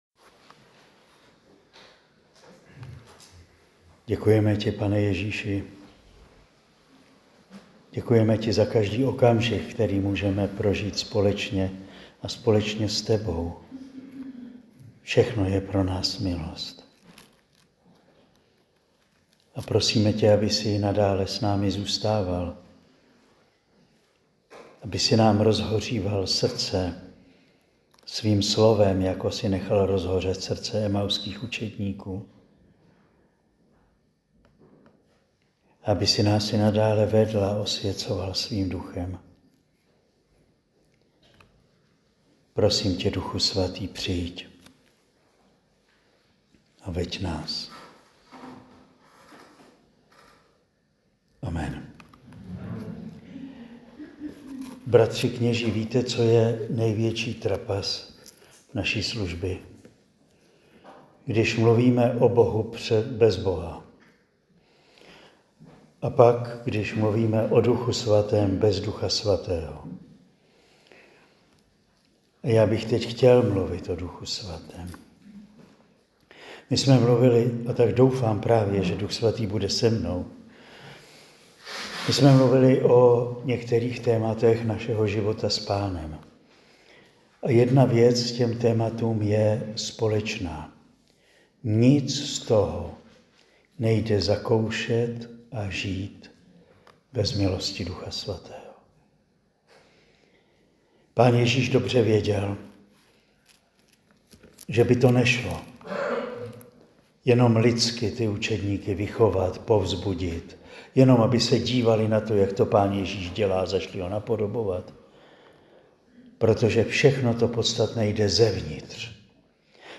Promluvy zazněly na exerciciích pro řeckokatolické kněze a jejich manželky v Juskovej Voli na Slovensku v listopadu 2025.